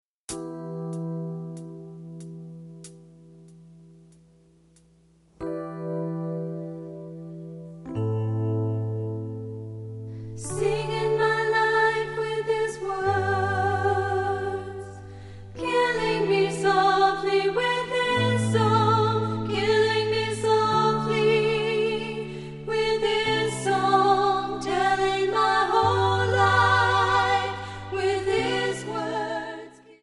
Backing track files: Pop (6706)